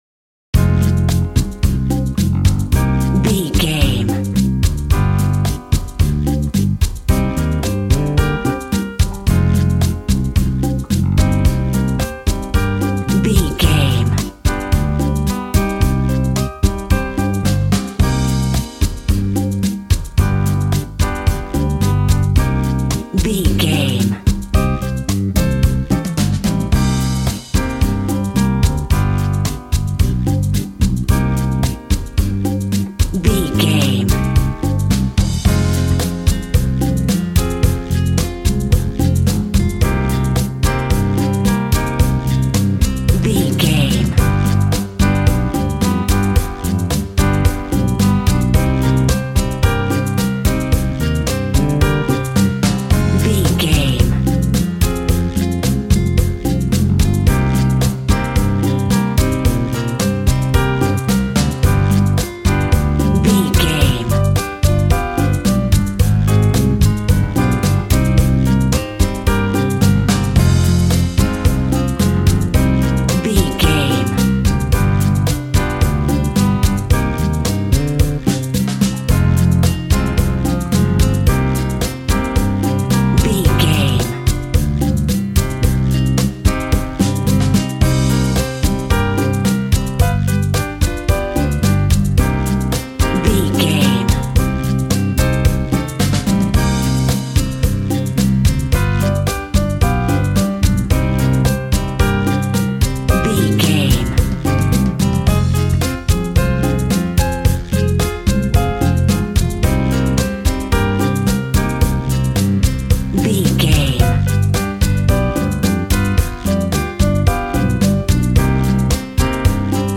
Aeolian/Minor
funky
energetic
romantic
percussion
electric guitar
acoustic guitar